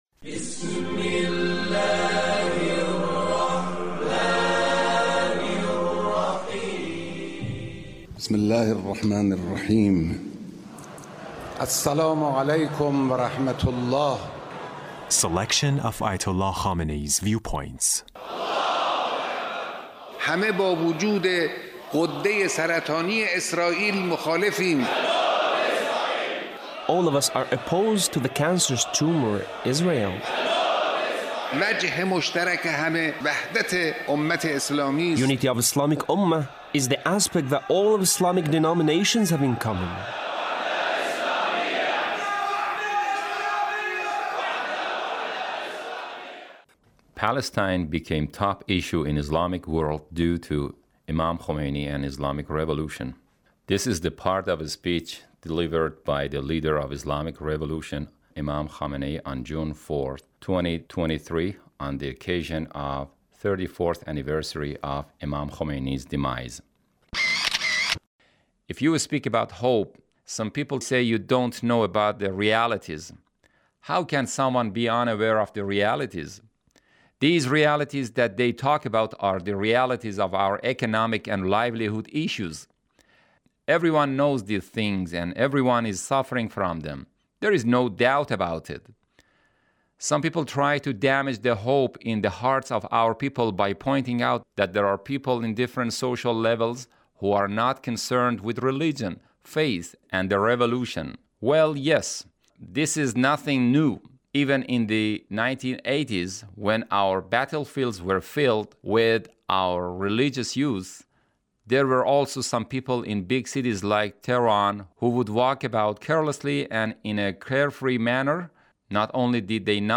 Leader's Speech (1746)
Leader's Speech on the occasion of the 34th ََAnniversary of Imam Khomeini’s Demise. 2024